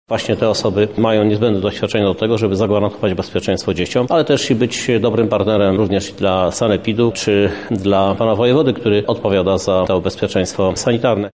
Ten okres pandemii oznacza, że potrzebne jest doświadczenie i dobra organizacja– mówi Prezydent Miasta Lublin Krzysztof Żuk: